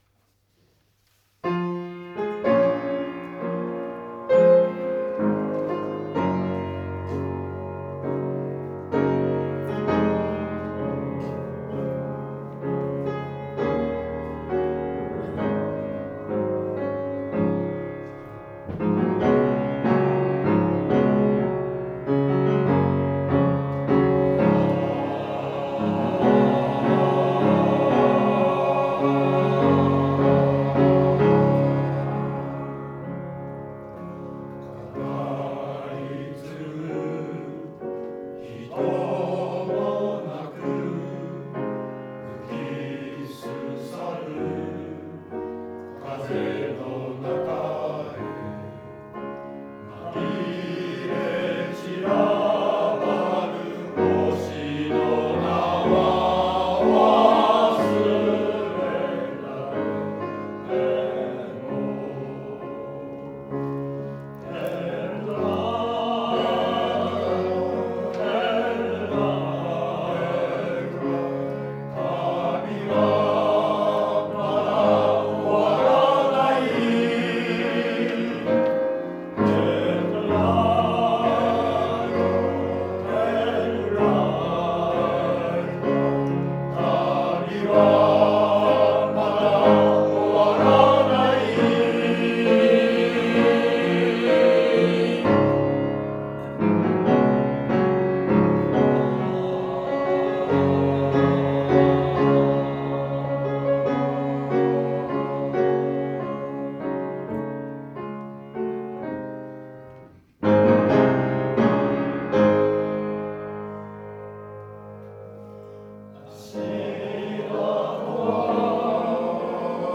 合唱祭前の最後の練習、湖北台東小学校
2)の演奏はお願いしたことを忘れかけているように思います。